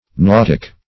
nautic - definition of nautic - synonyms, pronunciation, spelling from Free Dictionary Search Result for " nautic" : The Collaborative International Dictionary of English v.0.48: Nautic \Nau"tic\, a. [See Nautical .]